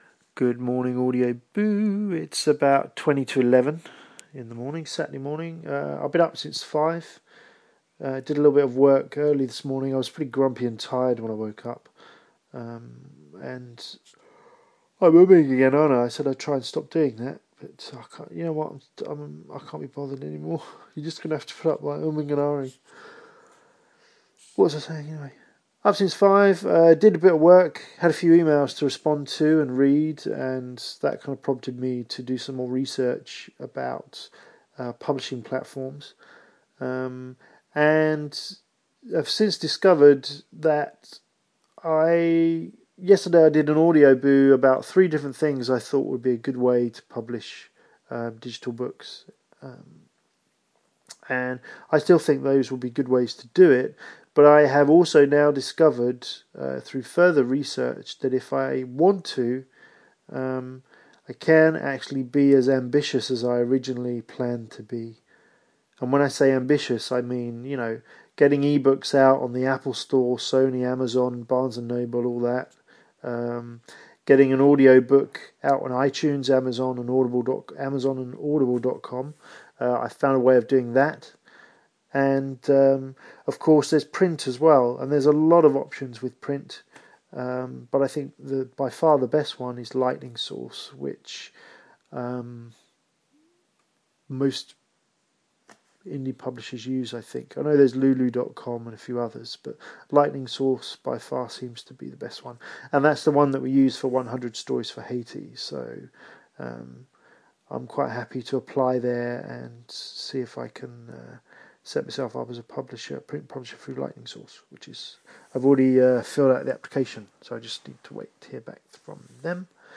At the end of this I sound like Joey from Friends, which can't be good ...